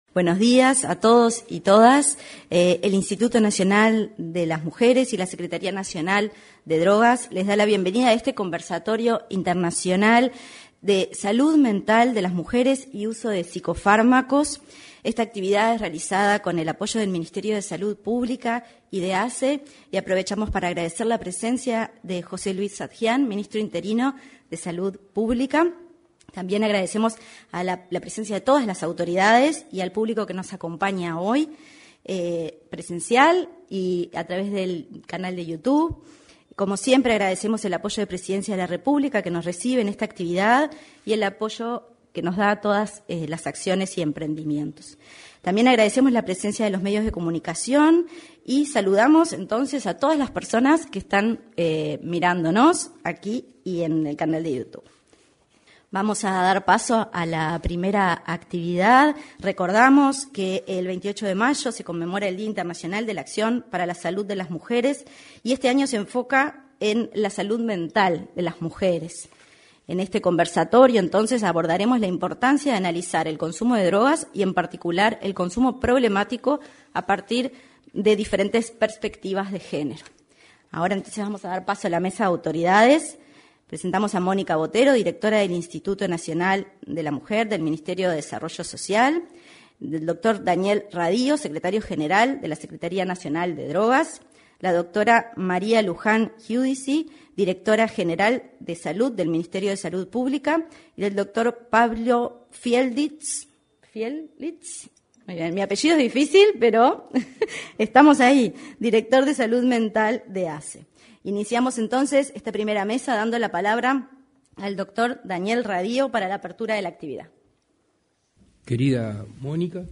Conversatorio Salud Mental de las Mujeres y Uso de Psicofármacos
Conversatorio Salud Mental de las Mujeres y Uso de Psicofármacos 08/06/2022 Compartir Facebook X Copiar enlace WhatsApp LinkedIn En el marco del Día Internacional de Acción por la Salud de las Mujeres, se llevó a cabo el conversatorio Salud Mental de las Mujeres y Uso de Psicofármacos. En la oportunidad, se expresaron el titular de la Secretaría Nacional de Drogas, Daniel Radío; el director de Salud Mental de ASSE, Pablo Fielitz; la subdirectora de Salud del MSP, Luján Giudici, y la directora del Instituto Nacional de las Mujeres, Mónica Bottero.